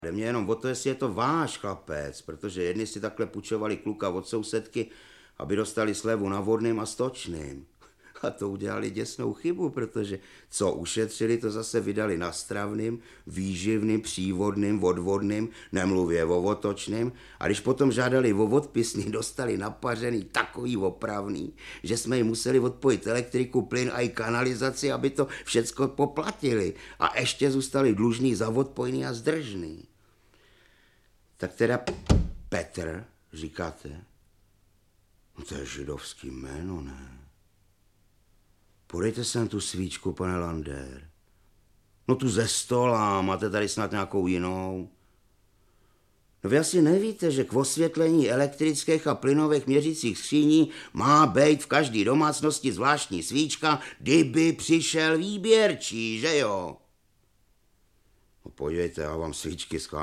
Audiobook
Read: Rudolf Hrušínský